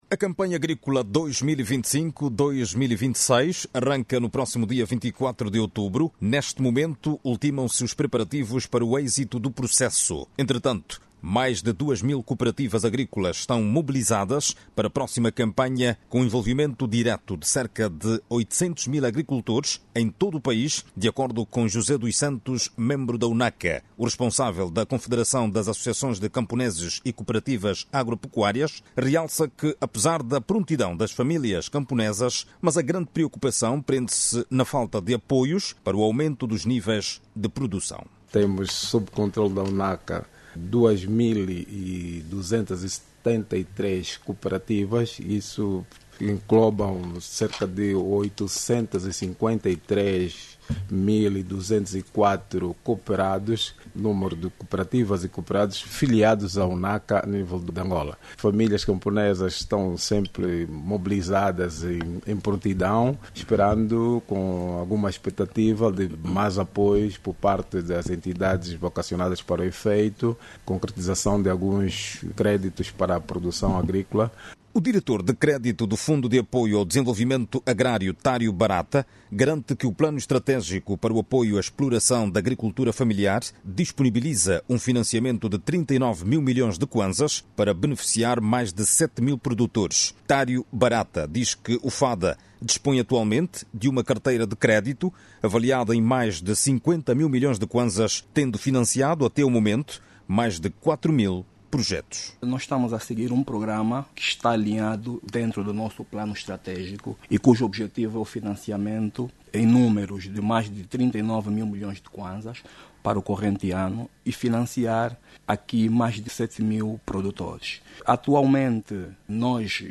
O Fundo de apoio ao Desenvolvimento Agrário “FADA”, tem disponíveis 39 mil milhões de kwanzas para financiar mais de 7 mil agricultores durante a Campanha Agrícola 2025/2026. Entretanto, mais de 800 mil camponeses filiados a UNACA estarão envolvidos directamente na próxima campanha agrícola. Clique no áudio abaixo e ouça a reportagem